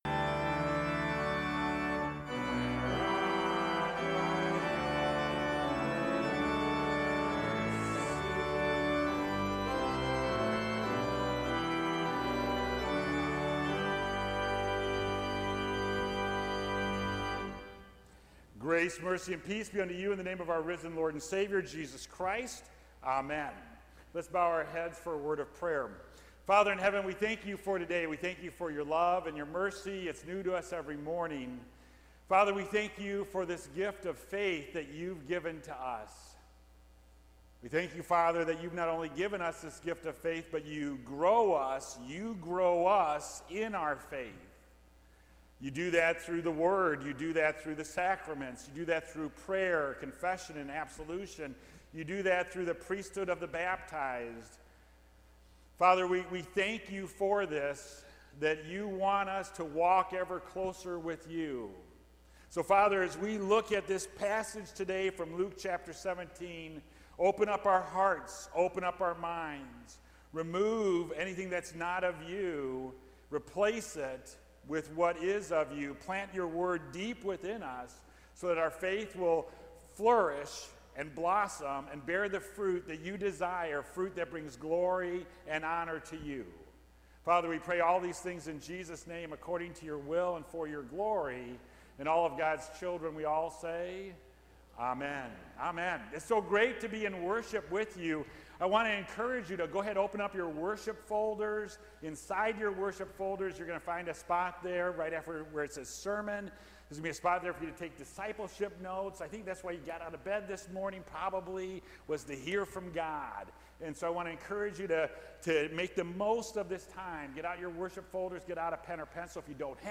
Current-sermon-1.mp3